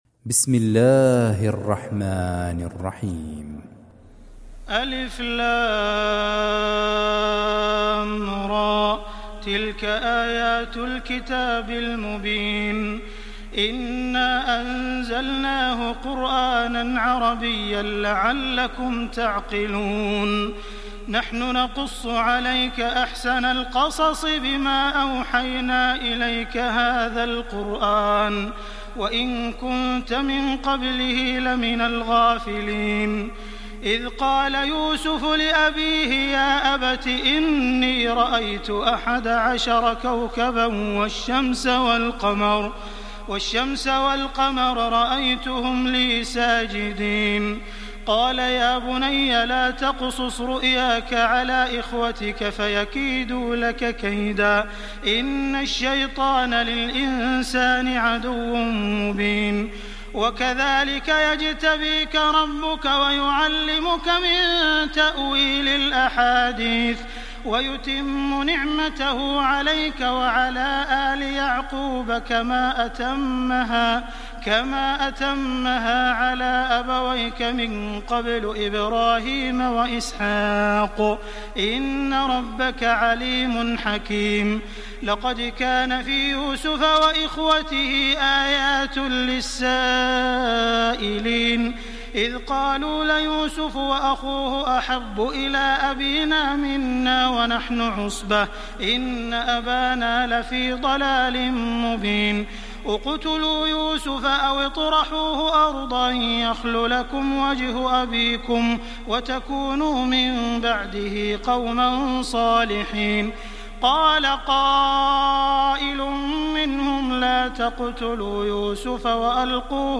استمع الى باقي السور للقارئ عبد الرحمن السديس